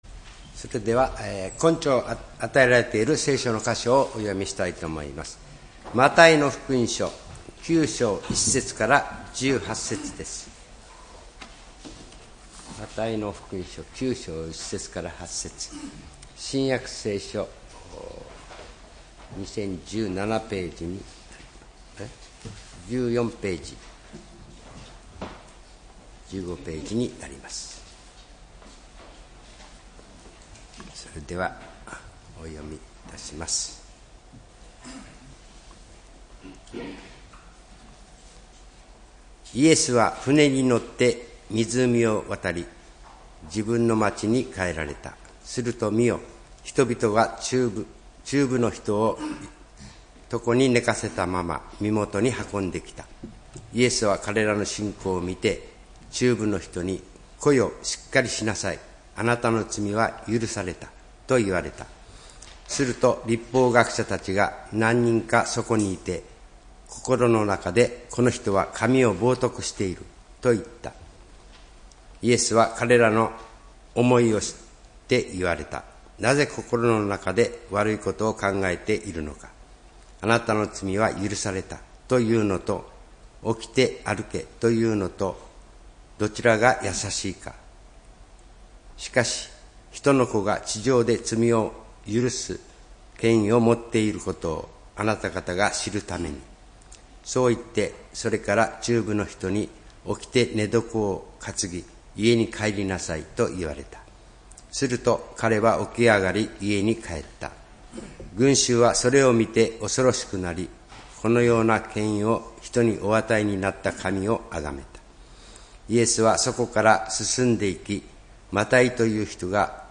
礼拝メッセージ「罪赦されて」（10月26日）